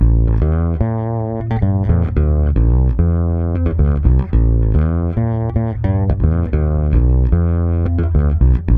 -AL AFRO B.wav